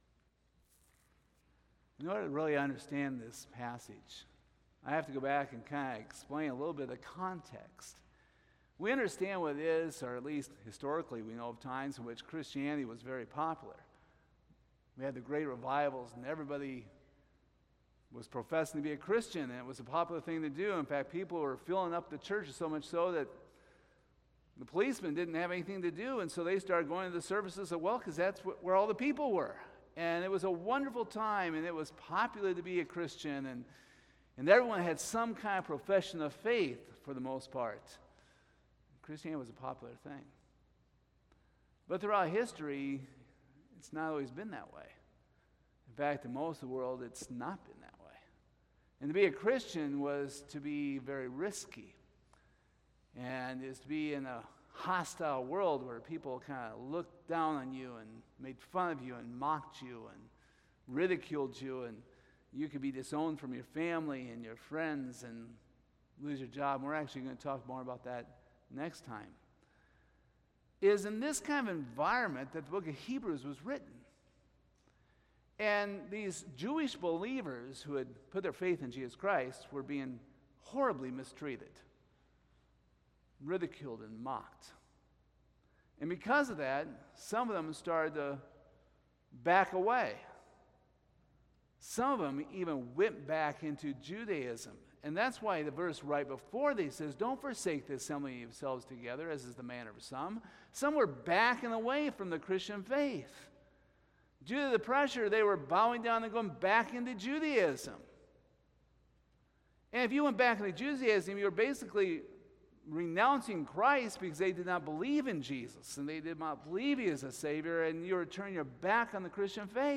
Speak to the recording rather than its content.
Service Type: Sunday Morning Topics: Apostasy , Backsliding , Questioning Salvation , Security of the Believer